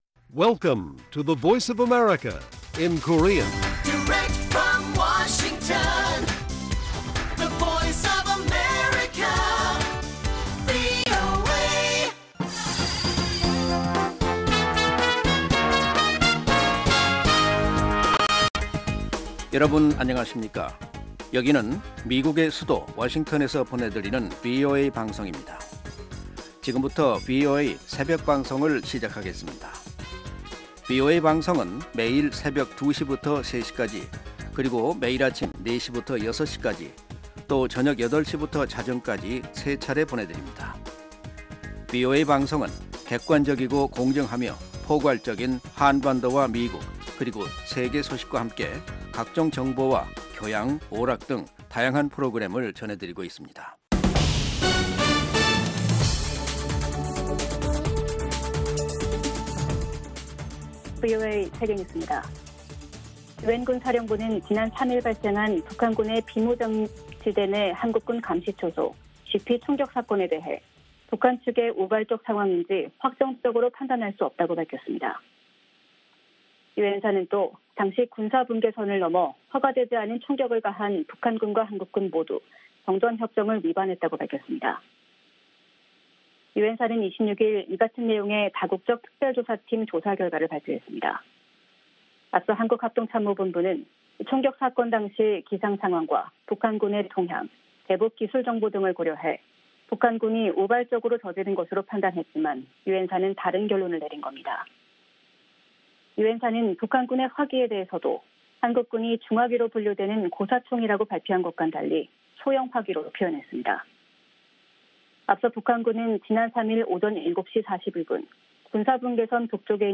VOA 한국어 '출발 뉴스 쇼', 2020년 5월 27일 방송입니다. 미국의 전직 관리들은 북한의 최근 당 중앙군사위 확대회의는 핵 역량 개발을 강화하겠다는 지난해 당 전원회의의 연장선이라고 분석했습니다.